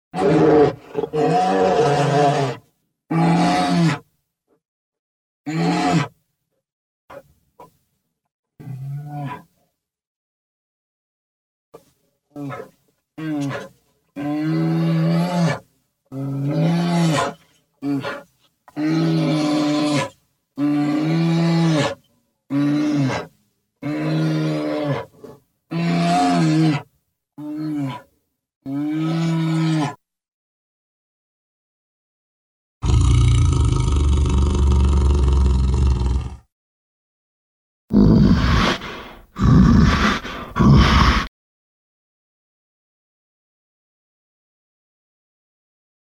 SFX – BEAR
SFX-BEAR.mp3